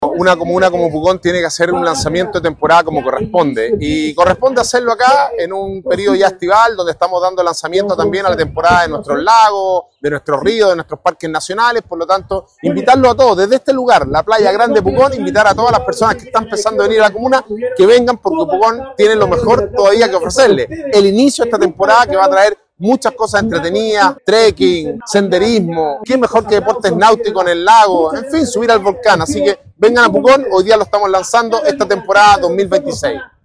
“Una comuna como Pucón tiene que hacer un lanzamiento de temporada como corresponde. Y corresponde hacerlo acá, en un periodo ya estival, donde estamos dando lanzamiento también a la temporada de nuestros lagos, de nuestros ríos, de nuestros parques nacionales”, indicó -en la playa junto al Lago Villarrica- el alcalde Sebastián Álvarez, quien se atrevió a “invitar a todas las personas que están pensando en venir a la comuna, que vengan porque Pucón tiene lo mejor que puede ofrecerle. El inicio de esta temporada va a traer muchas cosas entretenidas: Trekking, senderismo, qué mejor que deportes náuticos en el lago, en fin, o subir al volcán”.
Alcalde-Sebastian-Alvarez-destaca-el-lanzamiento-del-verano-con-muchos-atractivos-.mp3